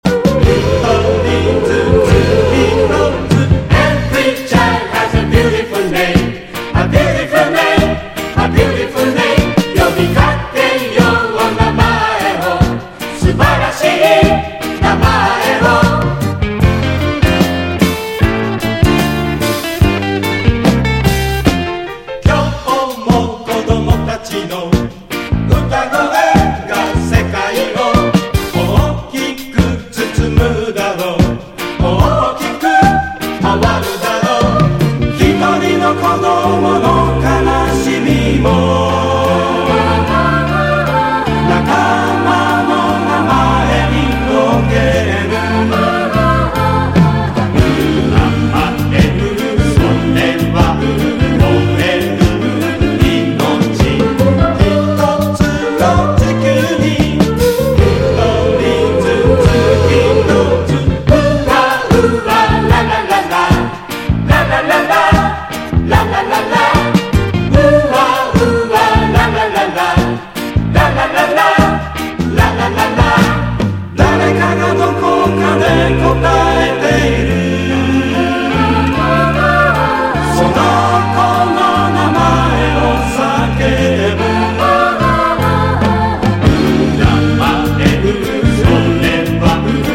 JAPANESE LOUNGE / EASY LISTENING
和モノ・ラテン/マンボなアレンジ！